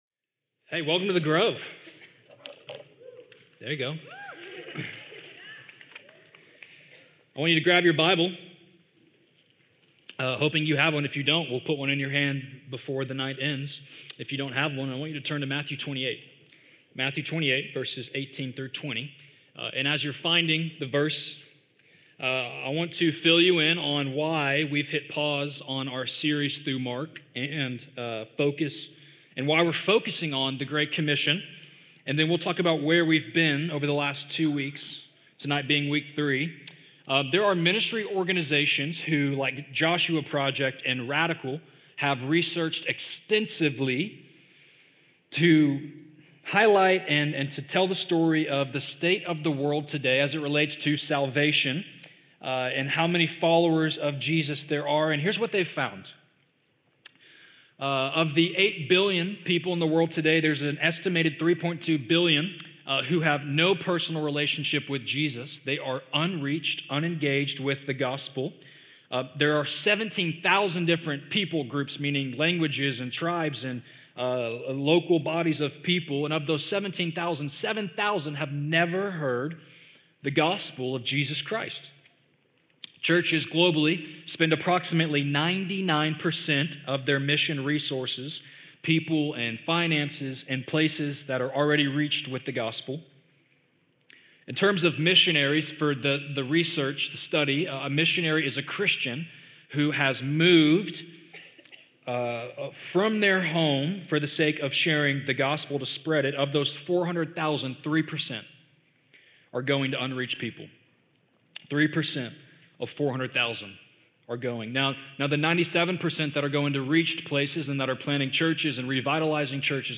In tonight's teaching, we look at what the Great Commission means for every believer. Join us as we answer some of the most common questions as it relates to living out the Great Commission, and how to share your faith.